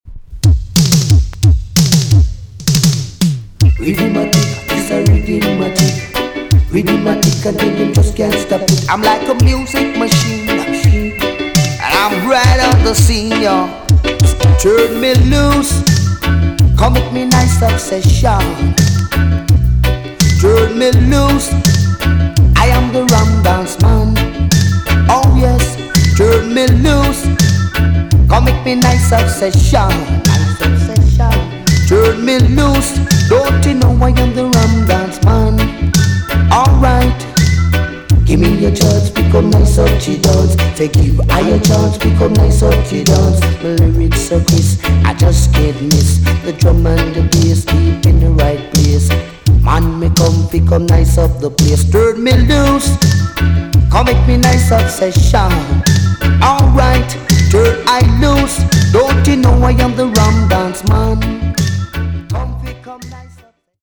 TOP >DISCO45 >80'S 90'S DANCEHALL
EX- 音はキレイです。
NICE VOCAL TUNE!!